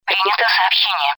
Ещё одна мелодия для СМС.